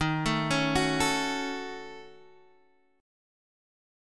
D# Minor 7th Flat 5th